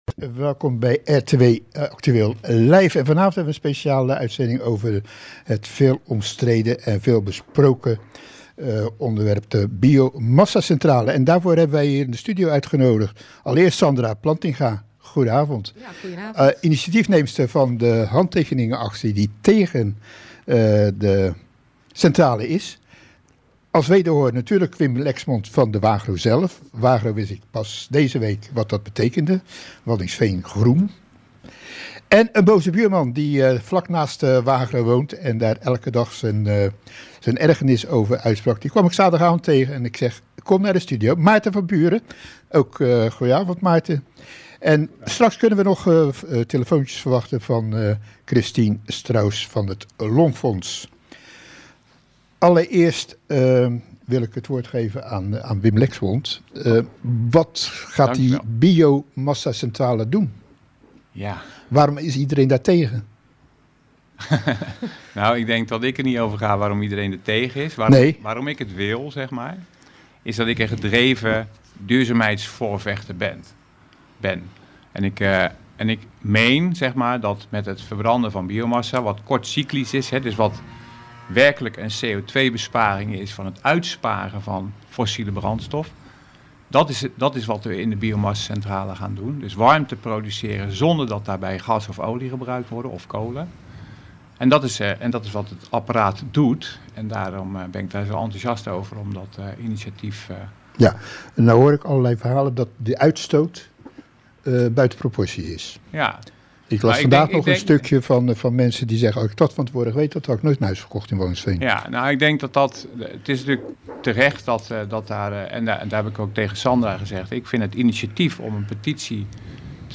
Interview bij RTW over biomassacentrales